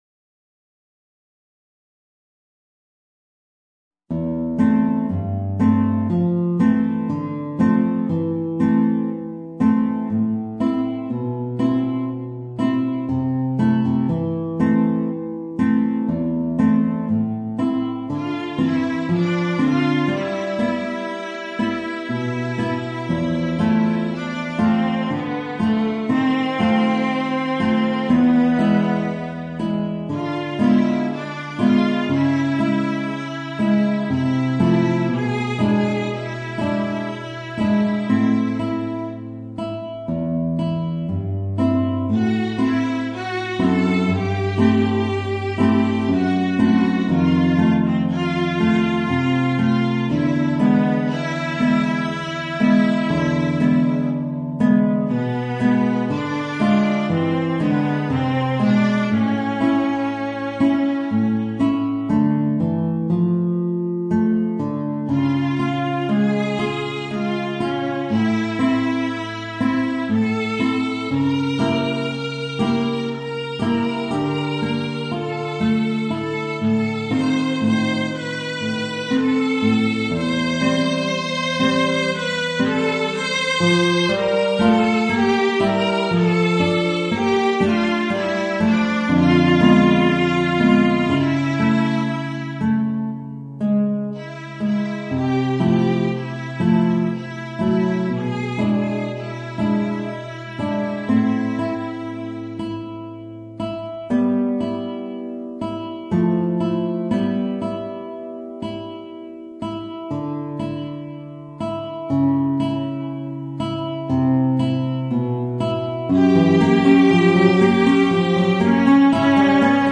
Voicing: Viola and Guitar